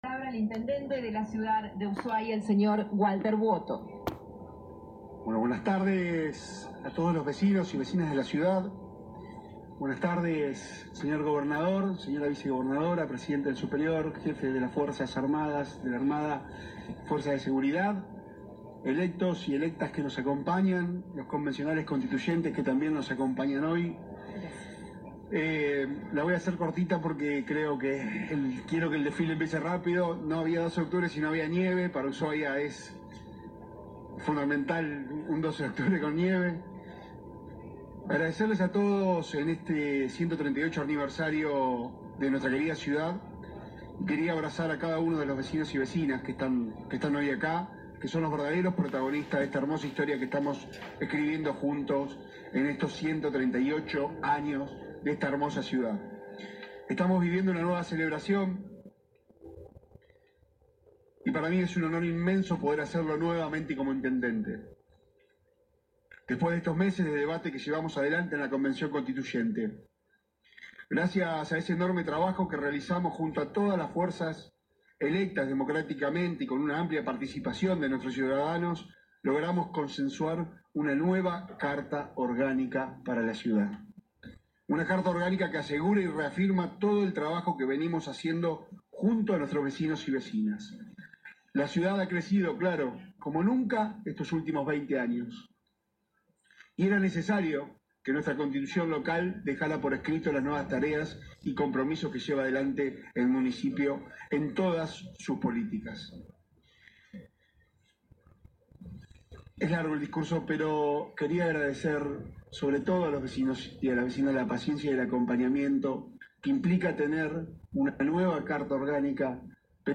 Ushuaia 12/10/2022.- El intendente de Ushuaia dio un breve discurso antes del inicio del desfile cívico militar por el 138° aniversario de la Capital Fueguina, destaco el trabajo realizado para los próximos 20 años y adelanto un gran trabajo en obra publica como dinamizador de la economía, de la que calificó, como la ciudad mas maravillosa de Argentina. Vuoto destaco el trabajo conjunto con el gobierno provincial y el sector privado en lo referente al desarrollo y crecimiento del sector turístico.